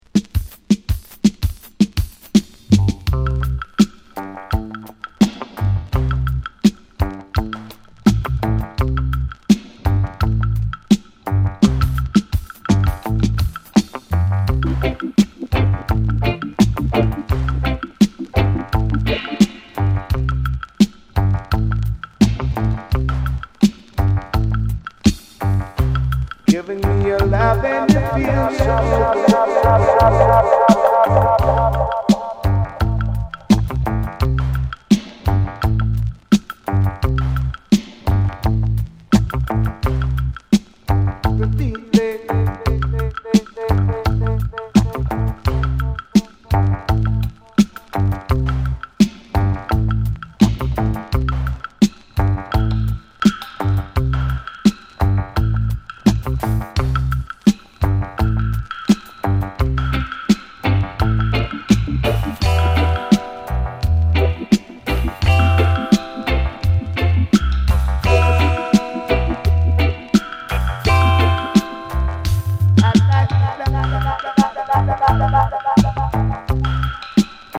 ジャマイカン・ラヴァーズとダンスホールの質感のリズムで心地良く揺られる名曲。